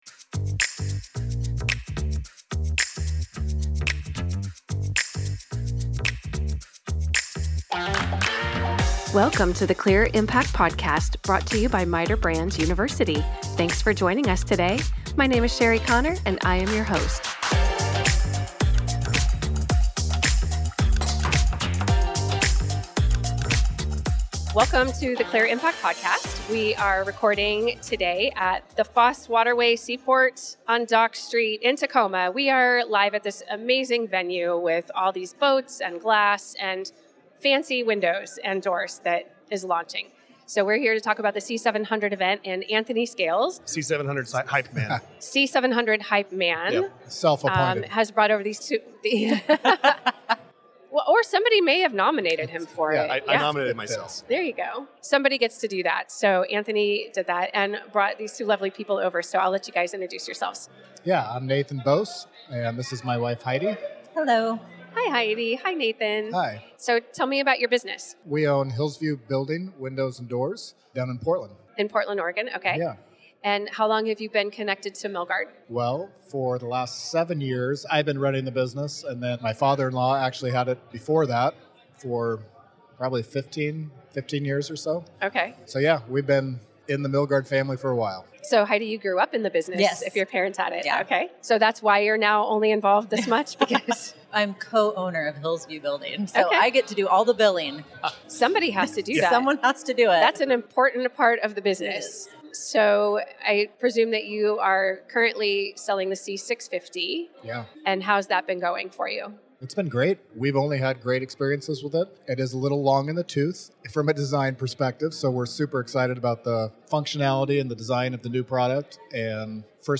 This episode captures real reactions from the C700 product pre-launch event in Tacoma. Hear how dealers, builders, and industry experts describe the features, installation, and market impact of MITER Brands’ newest fiberglass product line.